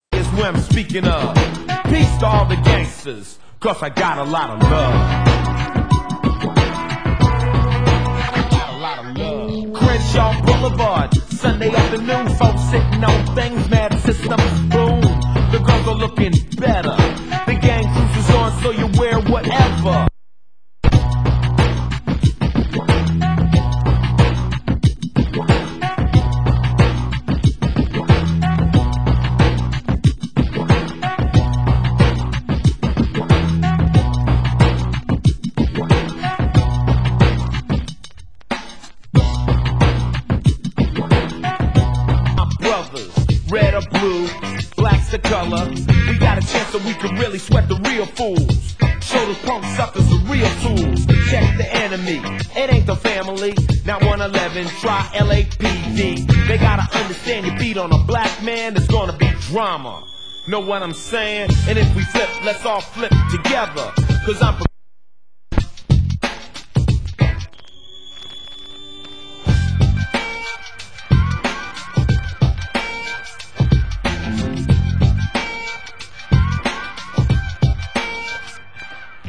Genre: Hip Hop
tubular bells mix